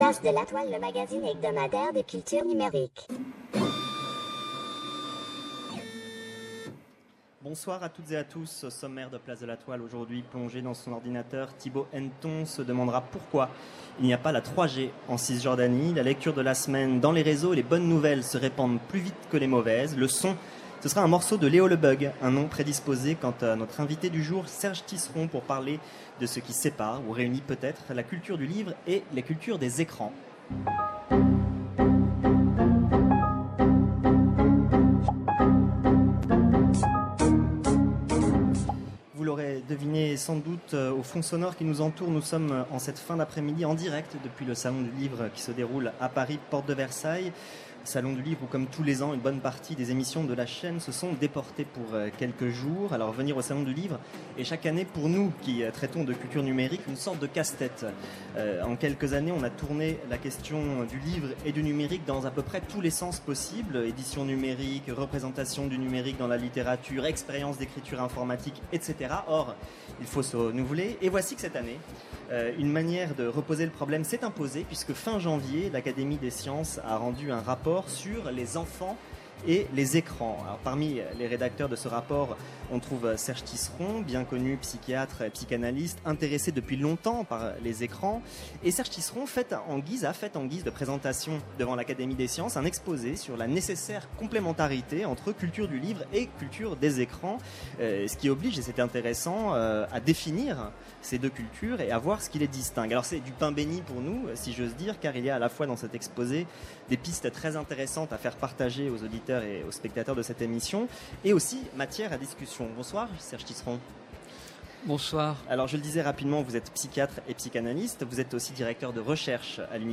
Vous l’aurez deviné sans doute au fond sonore qui nous entoure, nous sommes en cette fin d’après-midi en direct depuis le Salon du Livre qui se déroule porte de Versailles, à Paris, où, comme tous les ans, une bonne partie des émissions de la chaîne se sont déportées pour quelques jours.